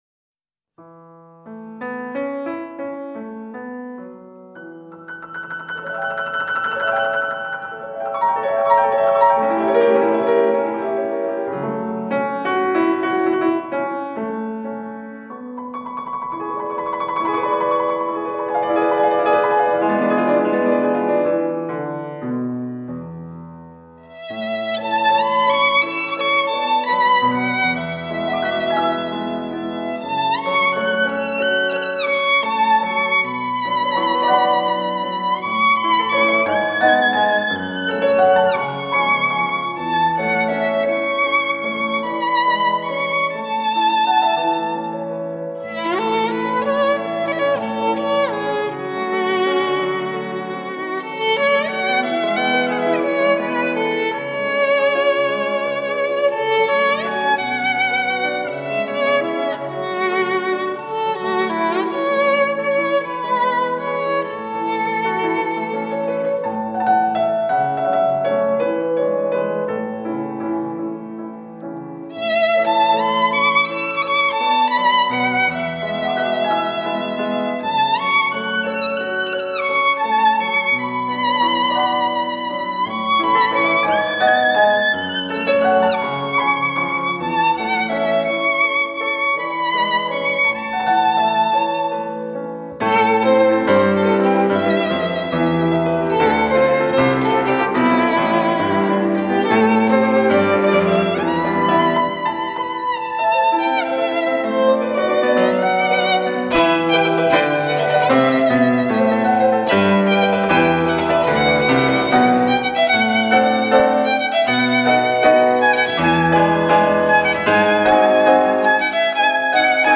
【抒情曲】
小提琴演奏
钢琴伴奏
1781年意大利名琴之声，唯美抒情的音乐旋律，动人心弦的华美乐章，